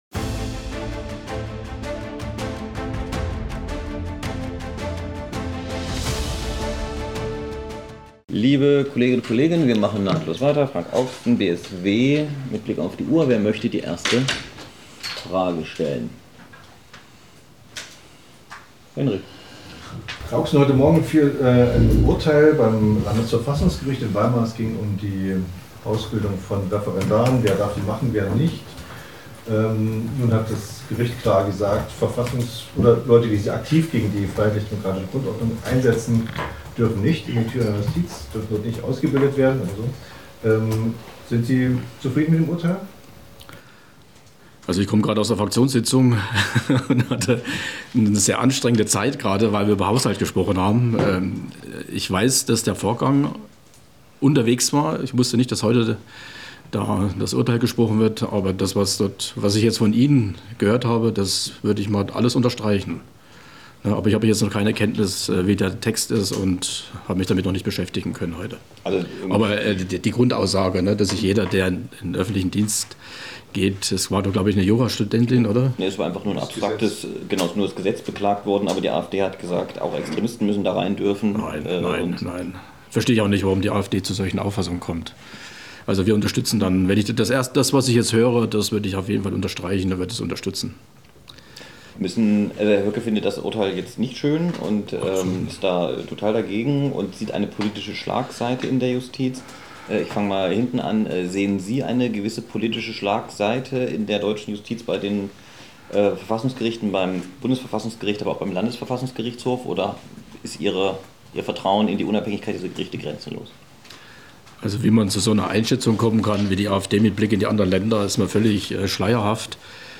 Was aber wird gefeiert? Eine spannende Umfrage durch Erfurt.